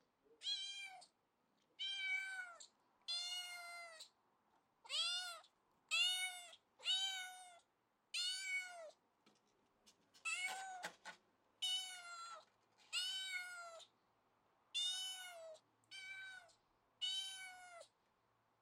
Мяукающий звук котенка